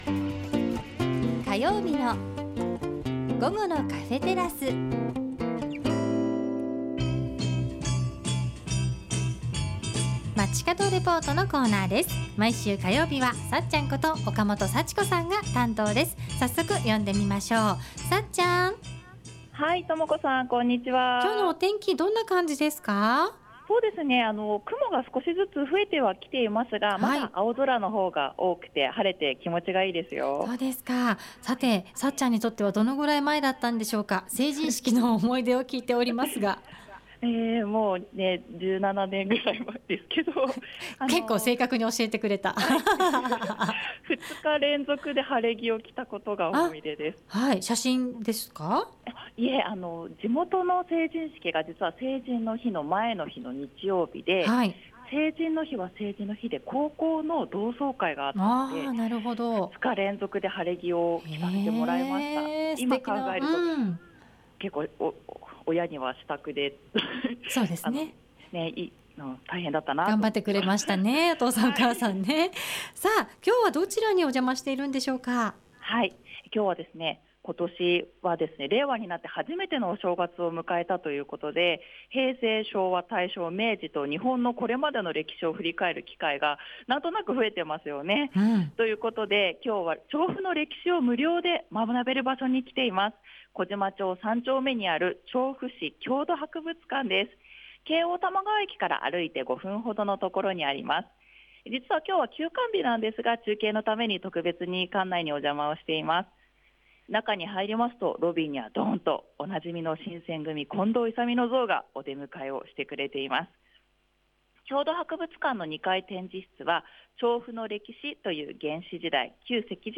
今日は休館日なのですが、中継のために特別に館内にお邪魔させていただきました。